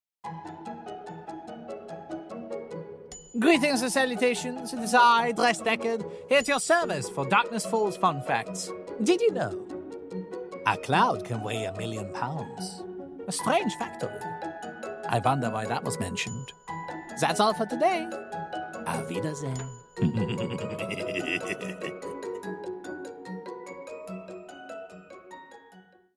Genres: Comedy Fiction, Drama, Fiction
Trailer: